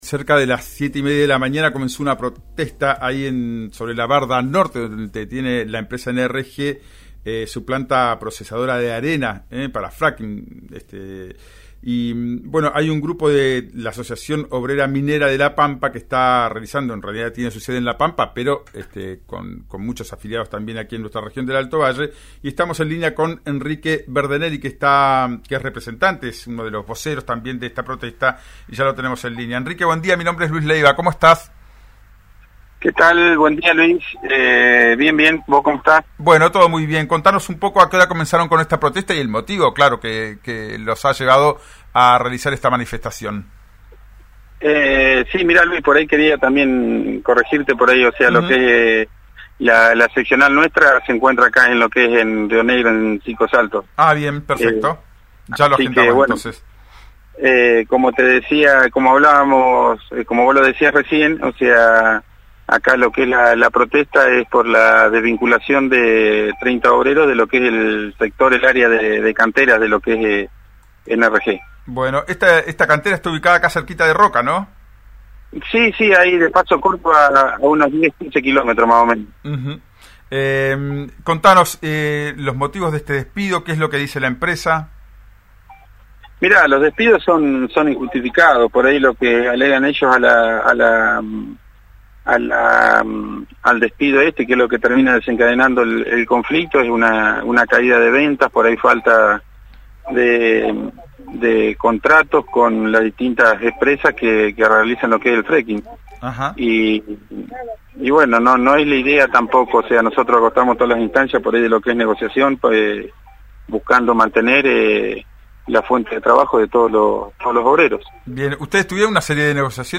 en «Ya es tiempo» por RÍO NEGRO RADIO.